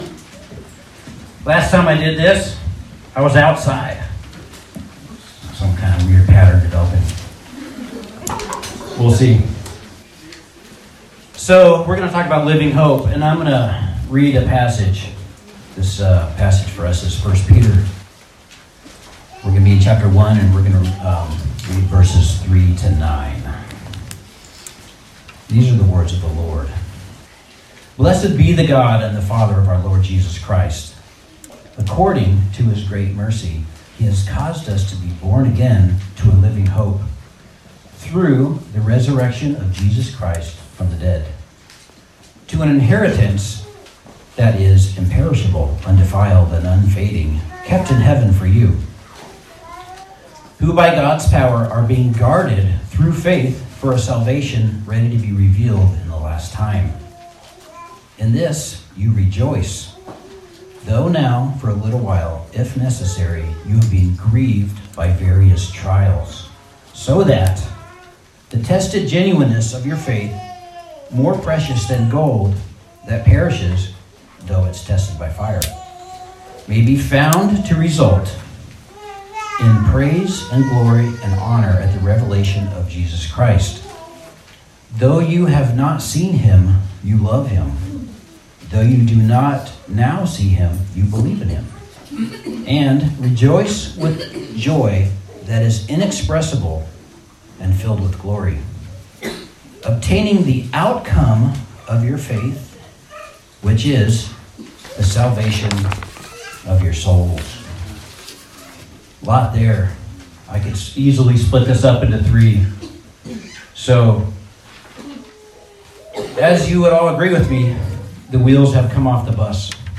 Passage: 1 Peter 1:3-9 Service Type: Sunday Service